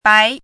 chinese-voice - 汉字语音库
bai2.mp3